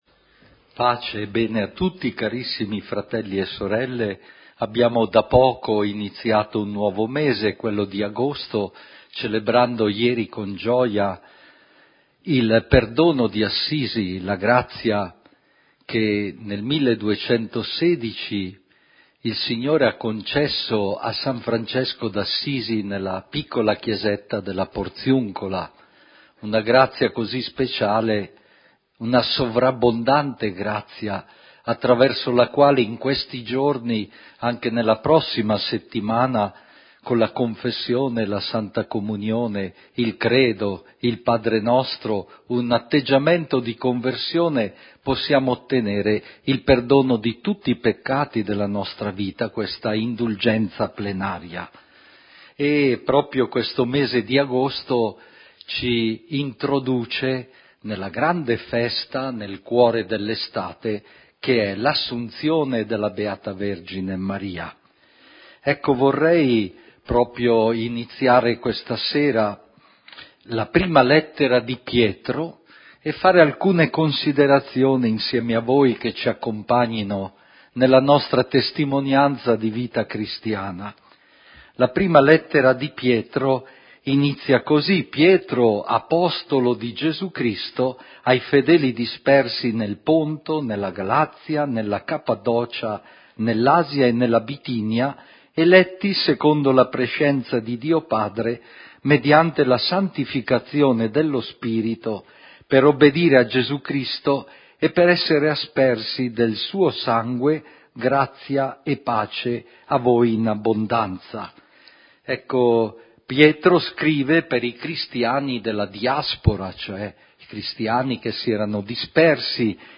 Dalla Cappellina di Maria: Serata mariana eucaristica